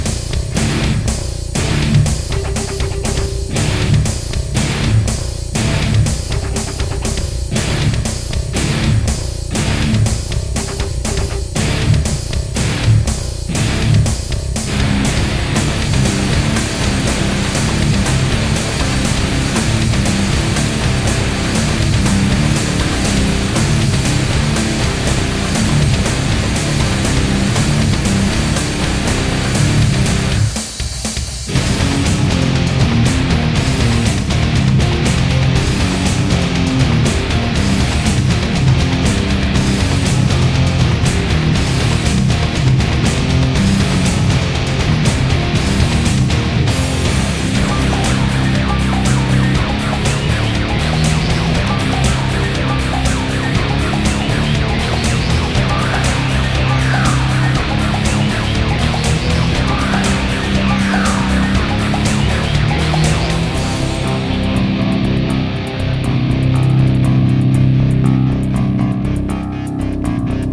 heavy guitar
these recordings are double-tracked directly over the stereo mix he gave me, so it's not really mixed.
guitar is $150 yamaha pacifica, bridge humbucker. this song is tuned to C. pretty much at the limit of where you can tune with 10's. next time i'm gonna use heavier strings for a tuning this low.